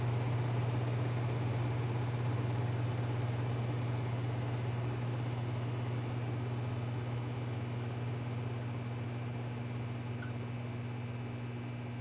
Ventelator.ogg